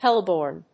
音節hel・i・borne 発音記号・読み方
/héləb`ɔɚn(米国英語), héləb`ɔːn(英国英語)/